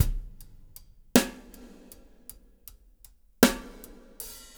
EPH DRUMS -R.wav